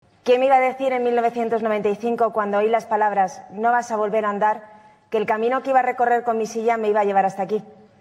En la ceremonia, la nadadora paralímpica fue una de los galardonados que tomó la palabra en representación de todos los demás durante la ceremonia y en su discurso relató su experiencia personal.